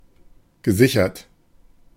Ääntäminen
IPA: [syʁ]